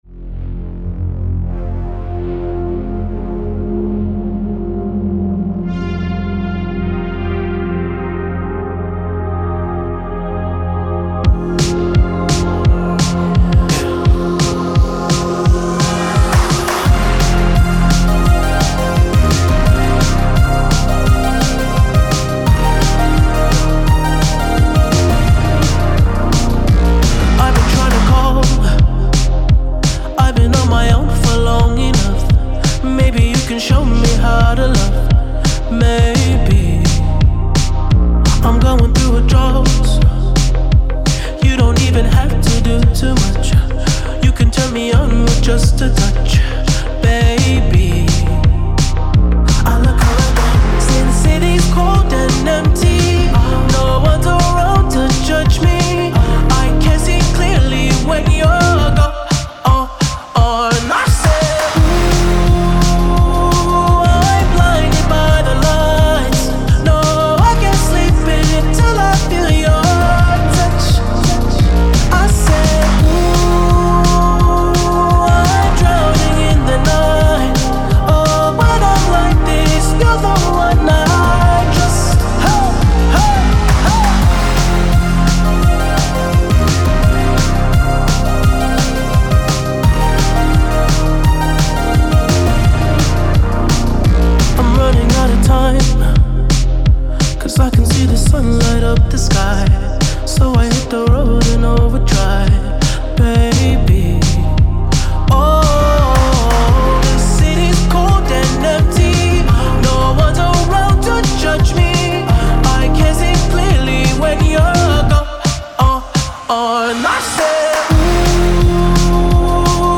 Жанр: Foreignrap